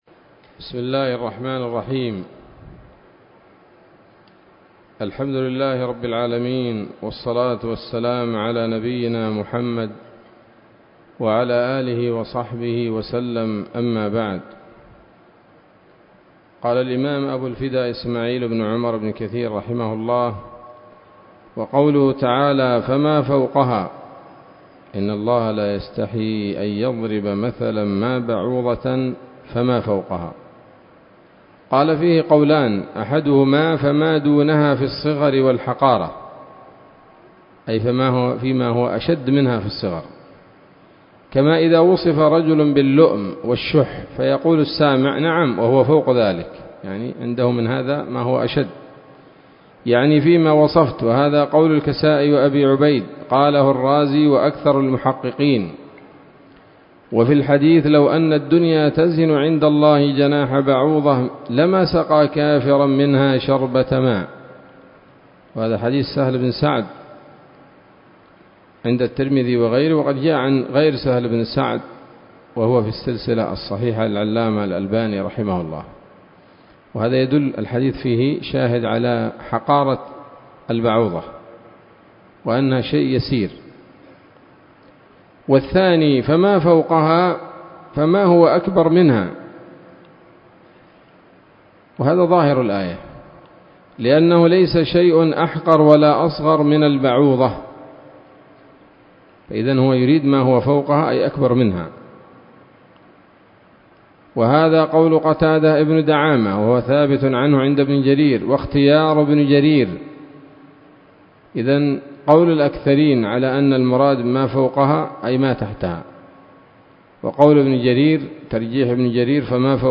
الدرس التاسع والعشرون من سورة البقرة من تفسير ابن كثير رحمه الله تعالى